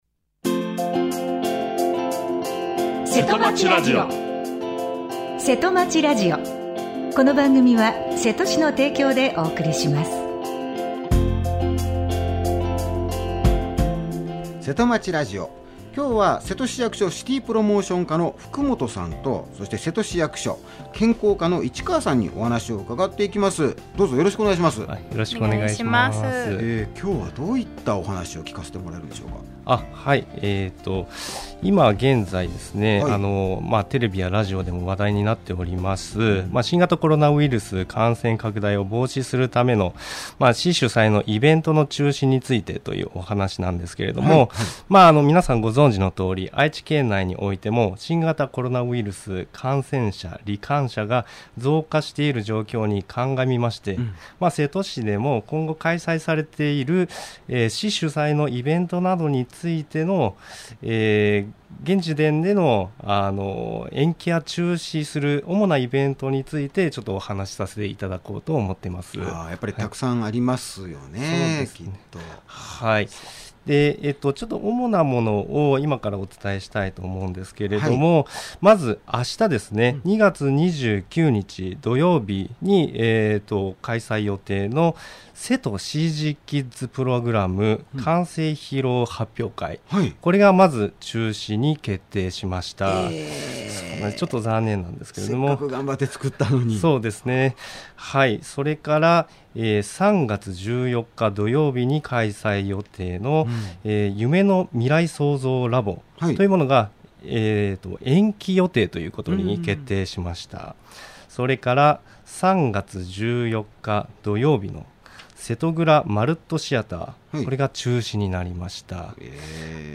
生放送